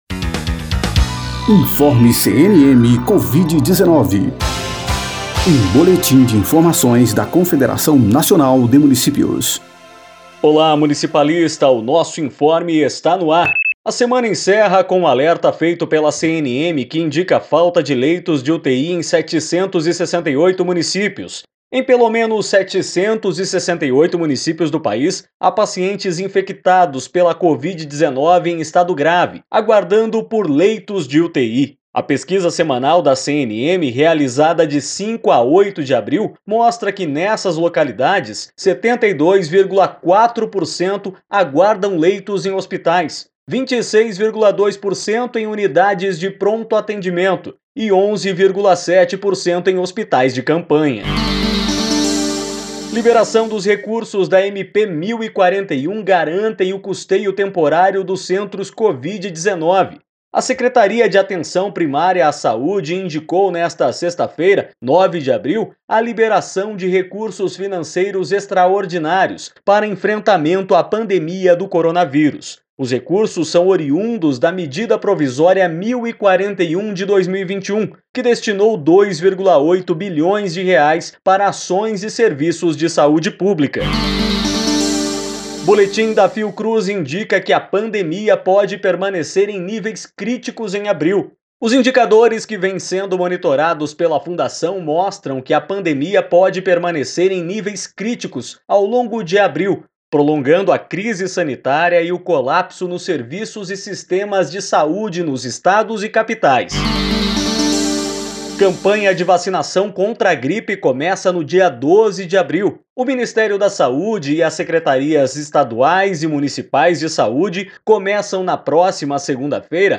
Boletim informe | COVID-19 - 09/4